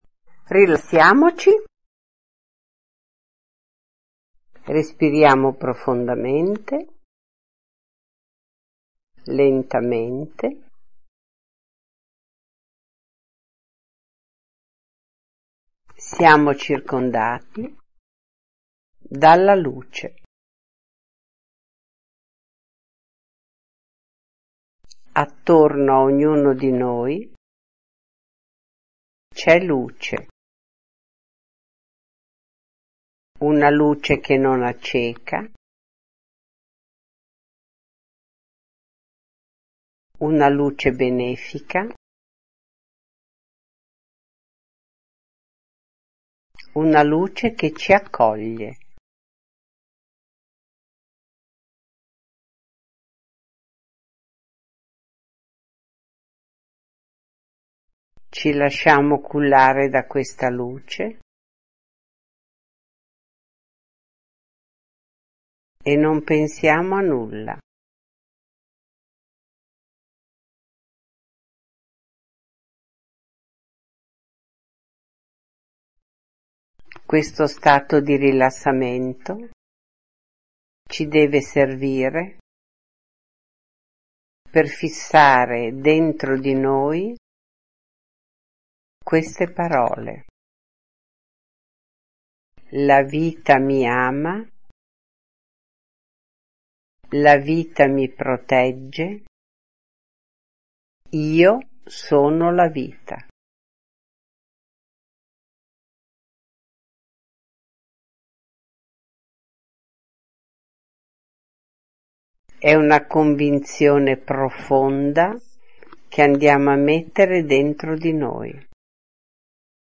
Piccoli nella Luce meditazione
Piccoli-nella-Luce-meditazione-1.mp3